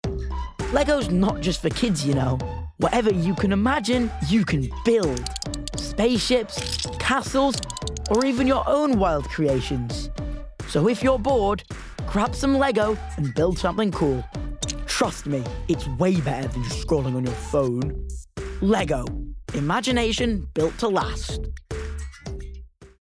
14-16 | Bright & Clear
Voice reel